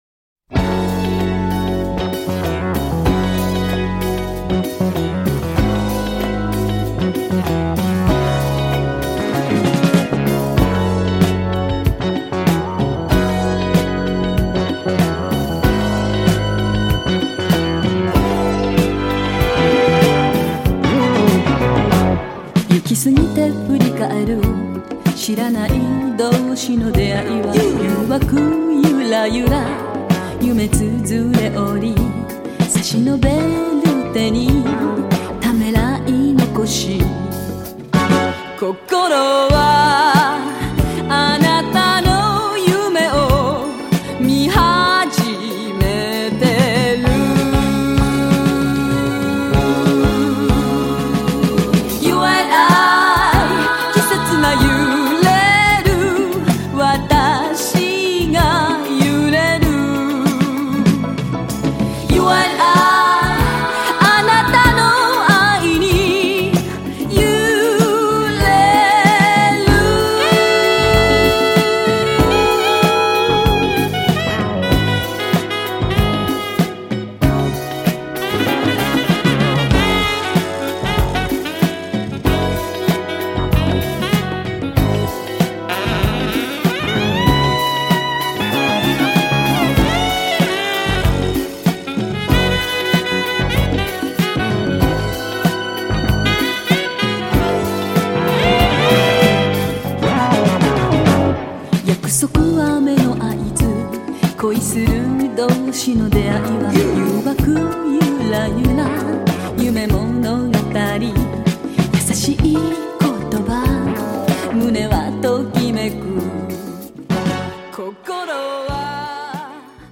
ジャンル(スタイル) JAPANESE POP CLASSIC / CITY POP